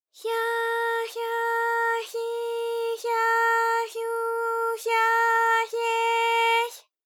ALYS-DB-001-JPN - First Japanese UTAU vocal library of ALYS.
hya_hya_hyi_hya_hyu_hya_hye_hy.wav